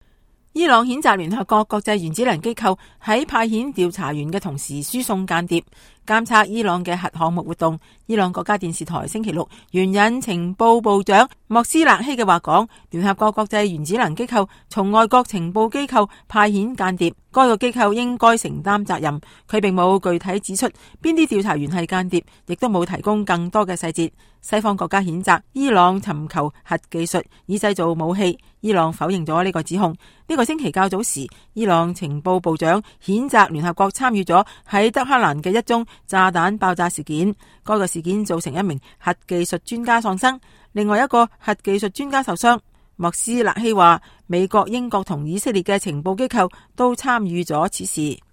CANNews_Iran_Nuclear.Mp3